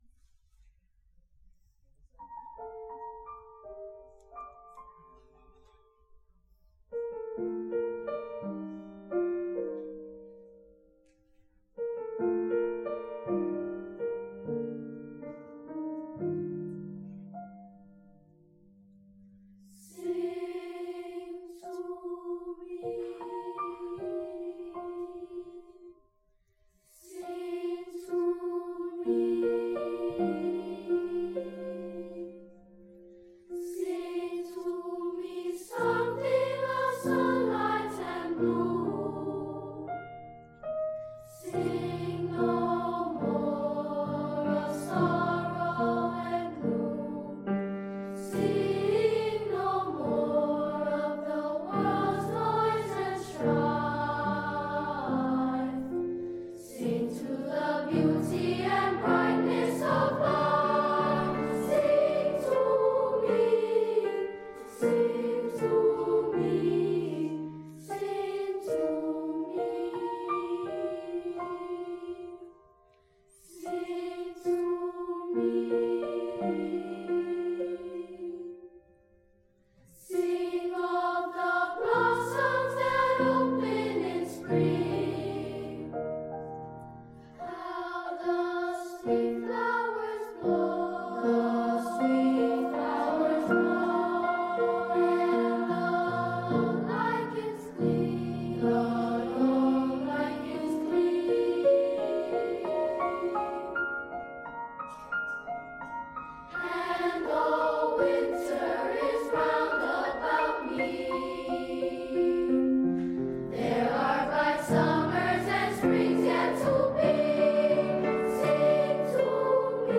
Sing to Me by Andrea Ramsey- at 2018 Benefit Concert
2018-Benefit-Concert-Sing-to-me.mp3